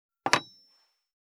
200,テーブル等に物を置く,食器,グラス,コップ,工具,小物,雑貨,コトン,トン,ゴト,ポン,ガシャン,ドスン,ストン,カチ,タン,バタン,スッ,サッ,コン,
効果音物を置く